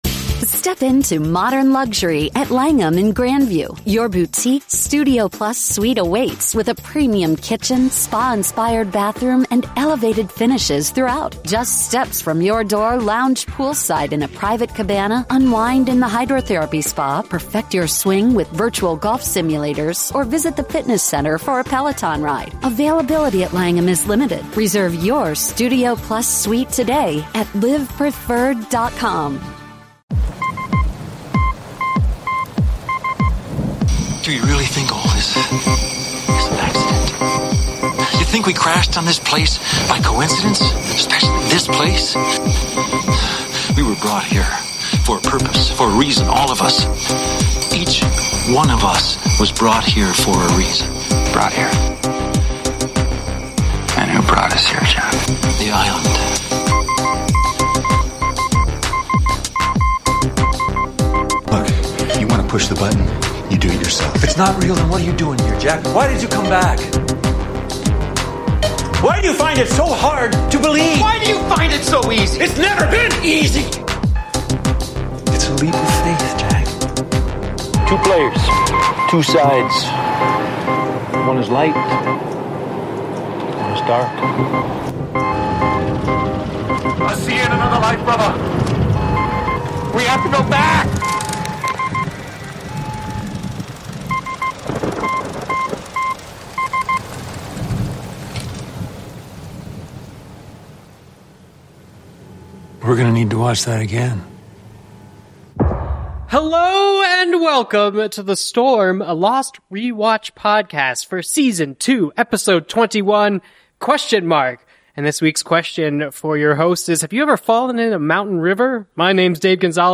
THE INTERVIEW (No Spoilers)